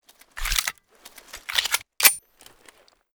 px4_reload_empty.ogg